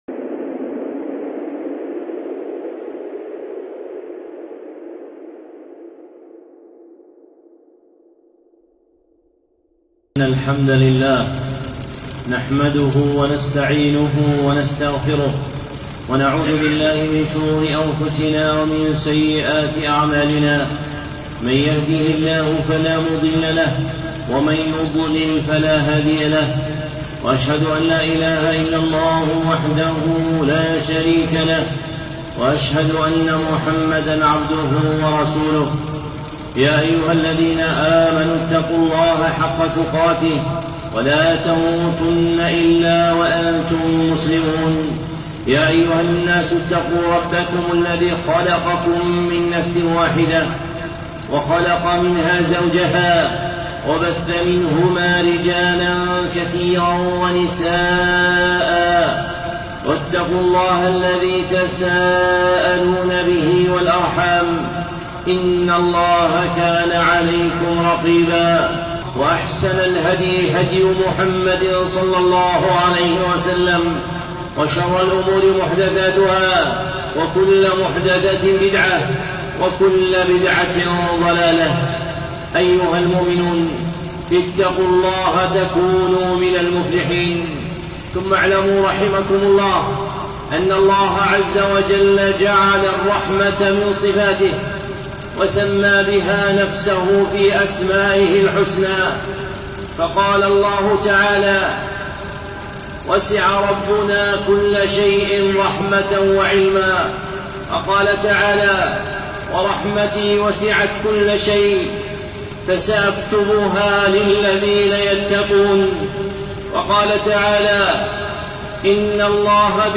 خطبة (رحمة الله للرحماء)